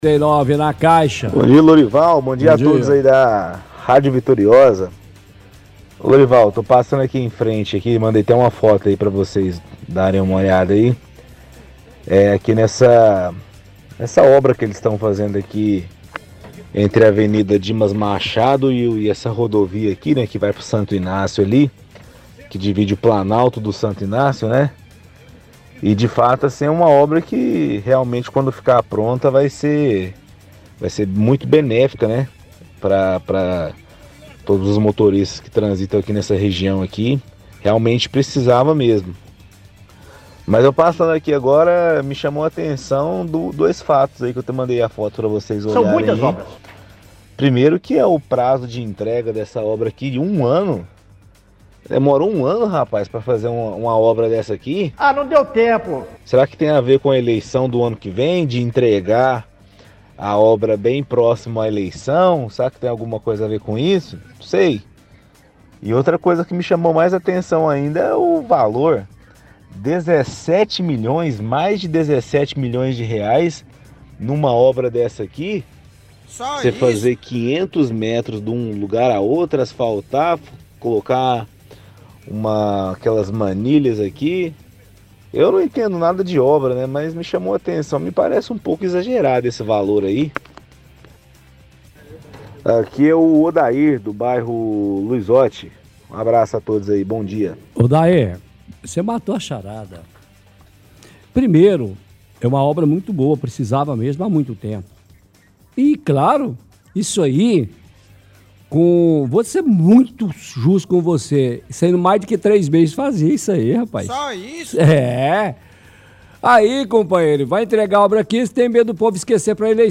– Ouvinte fala de obra no bairro Planalto e diz que vai ser uma obra muito benéfica para os motoristas. Fala sobre o prazo de entregue, que é de 1 ano, questiona se tem a ver com a eleição no ano que vem, e questiona o valor de mais de 17 milhões de reais.